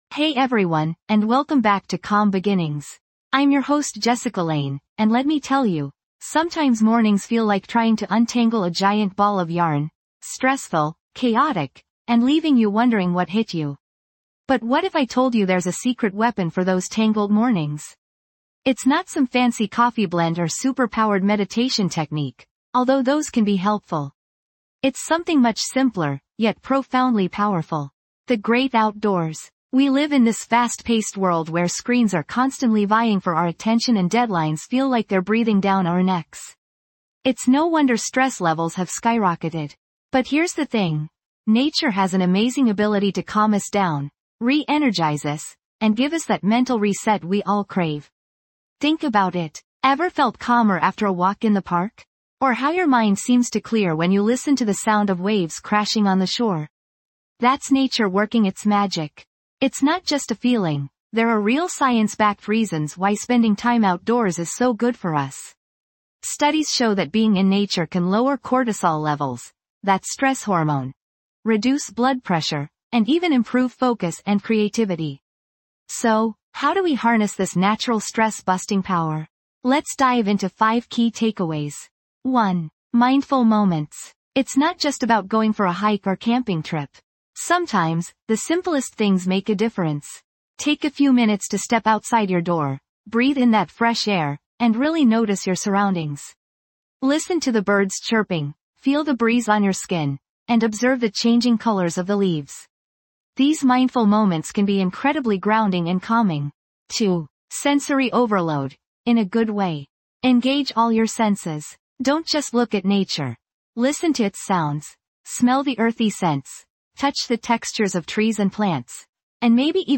Calm Beginnings | Peaceful Morning Reset guides you through a soothing journey of mindfulness and relaxation to gently awaken your mind and body. Each episode offers calming guided meditations, gentle affirmations, and peaceful soundscapes designed to melt away stress and set the tone for a positive and productive day.